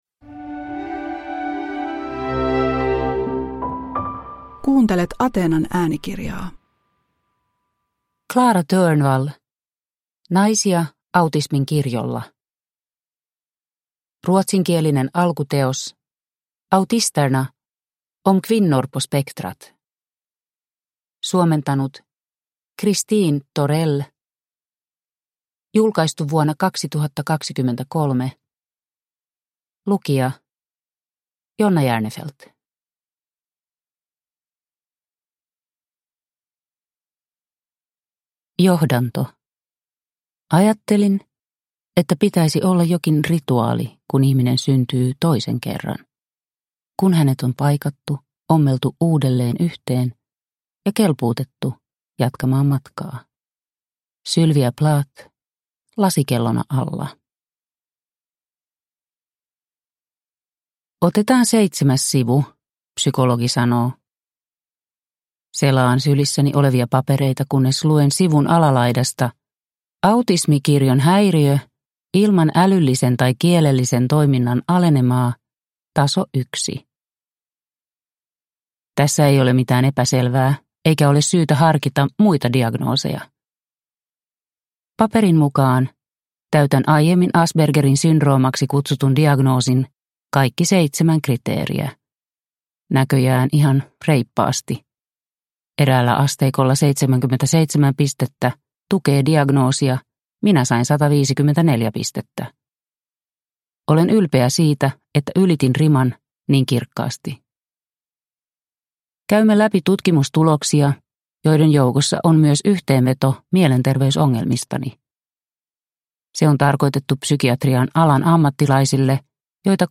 Naisia autismin kirjolla – Ljudbok